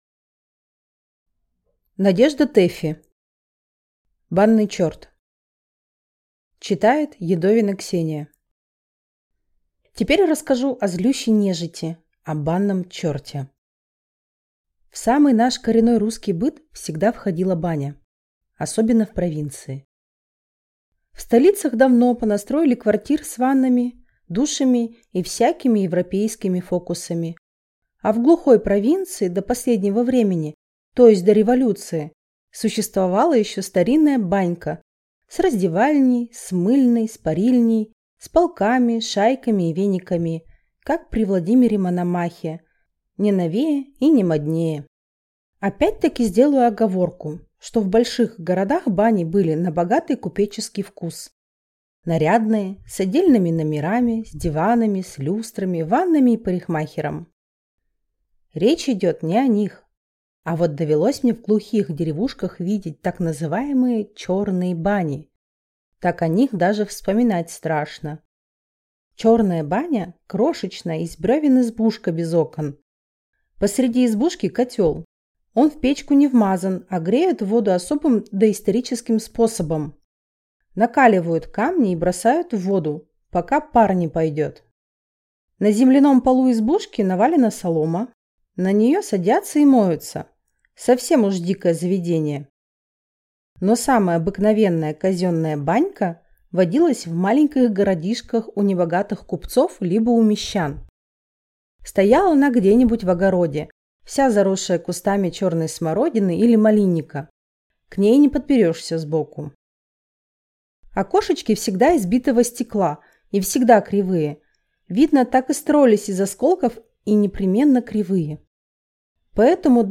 Аудиокнига Банный черт | Библиотека аудиокниг